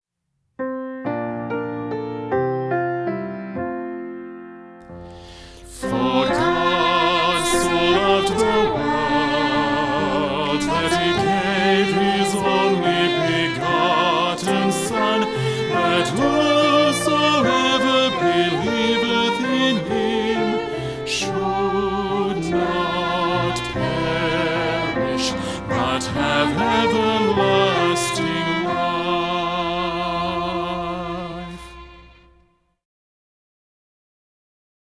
• 26 songs and 25 dialogue recordings.